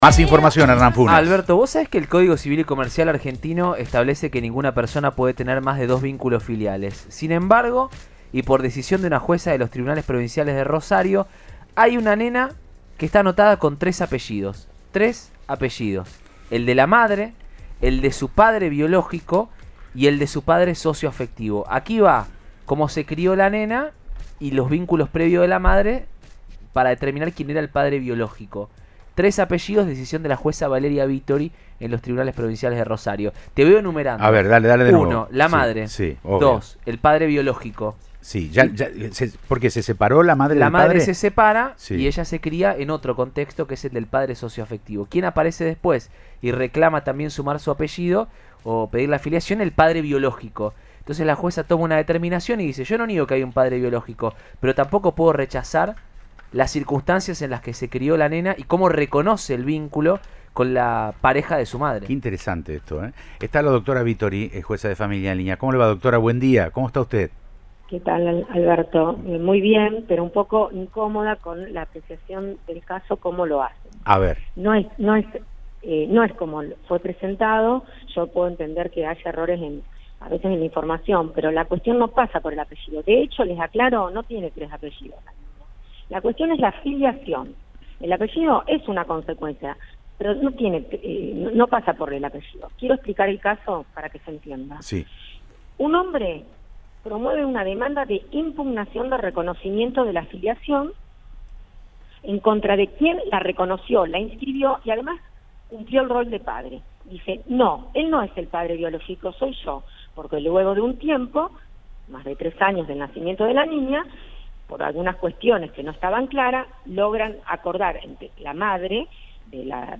La jueza Valeria Vittori, integrante del Tribunal Colegiado de Familia N° 7 de Rosario, explicó en Siempre Juntos, por Cadena 3 Rosario, los detalles de una decisión judicial muy particular: dispuso que una niña tenga, legalmente, dos padres y una madre.